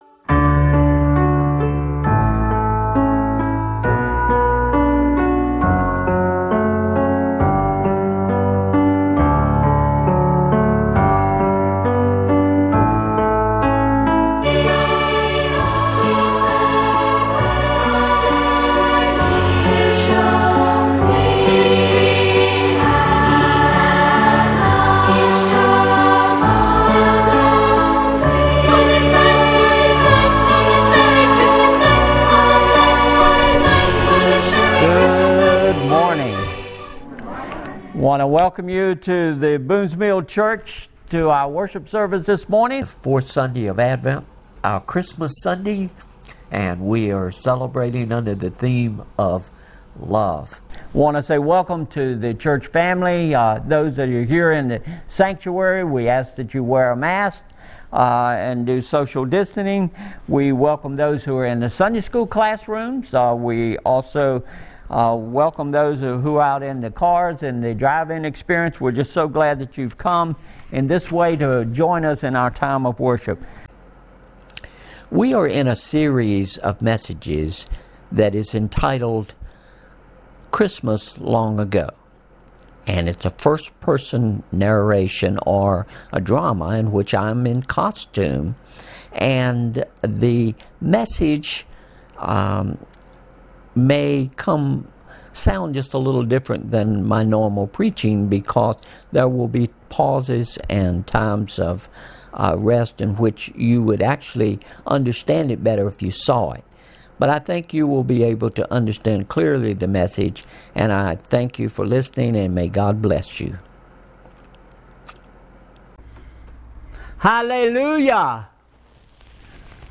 Sermon Series Christmas Long Ago – Part 4
(A first person narration/drama - by the Apostle John)